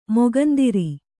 ♪ mogandiri